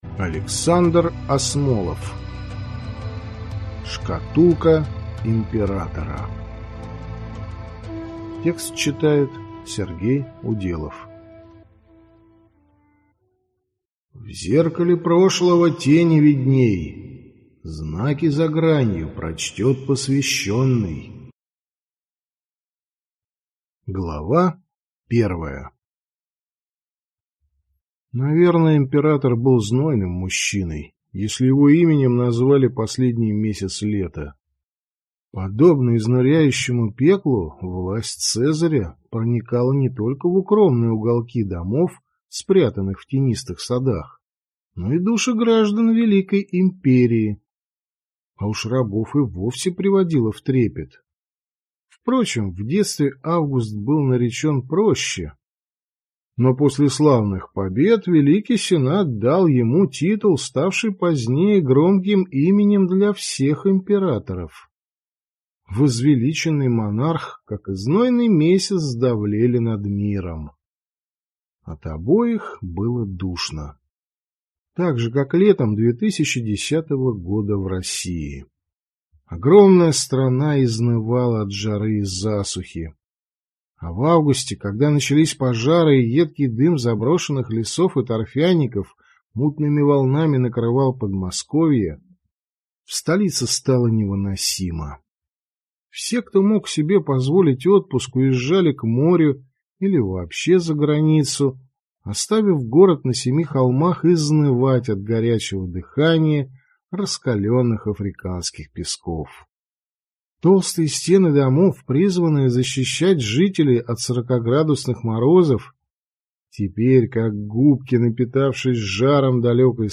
Аудиокнига Шкатулка императора | Библиотека аудиокниг